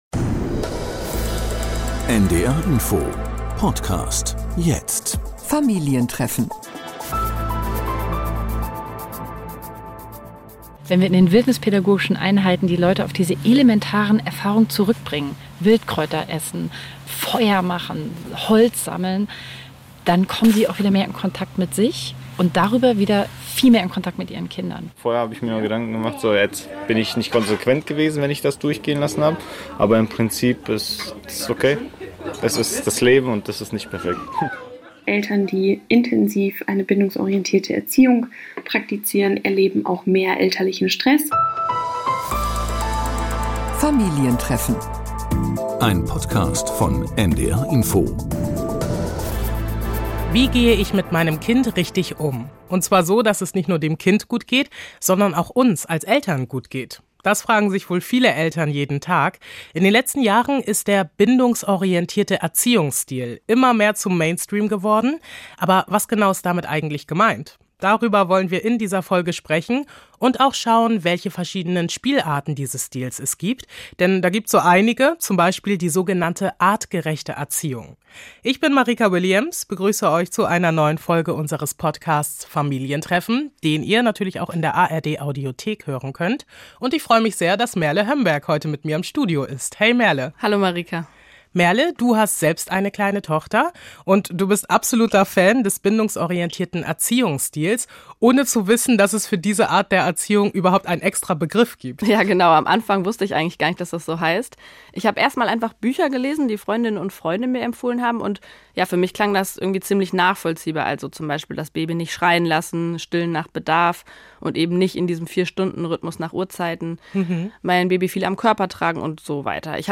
Wir haben ein Artgerecht-Camp im Münsterland besucht, wir sprechen mit einer Wissenschaftlerin, die untersucht hat, dass Bindungsorientierte Erziehung vor allem die Mütter mehr stresst als andere Erziehungsstile.